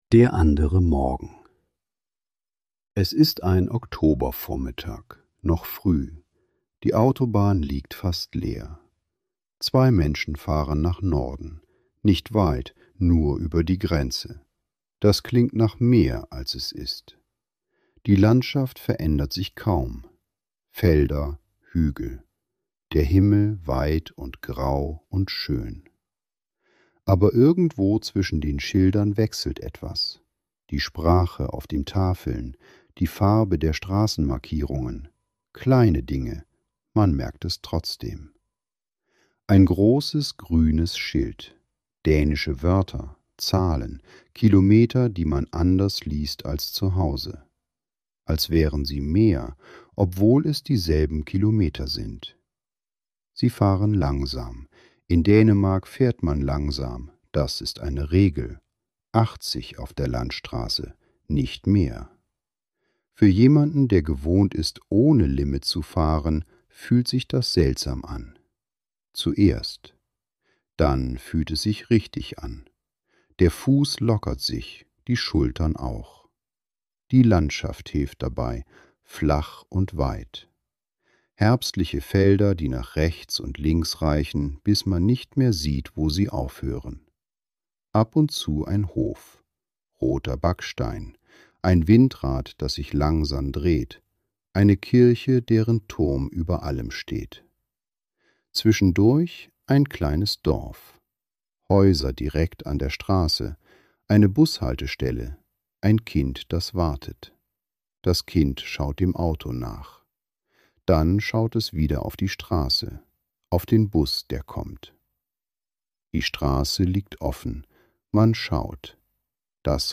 Die Folge ist eine ruhige Reiseerzählung über Ankommen, das Staunen über Kleinigkeiten und das Mitnehmen von Eindrücken, die länger nachklingen als die Fahrt zurück.